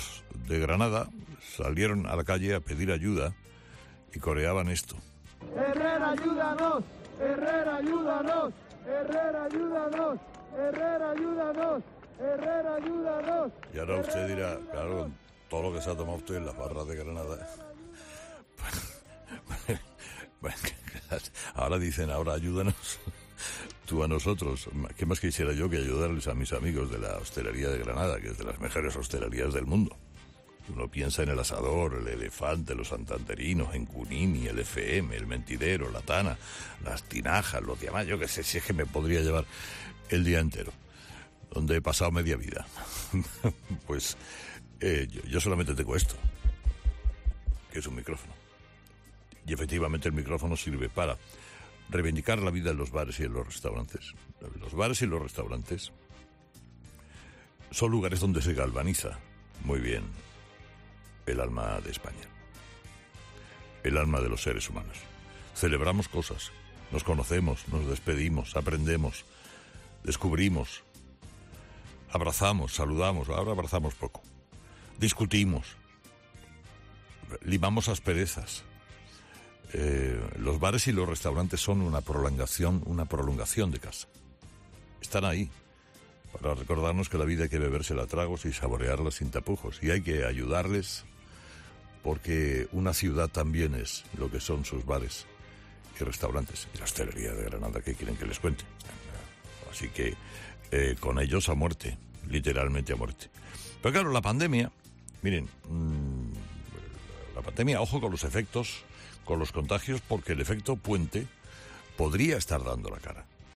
El trío de cómicos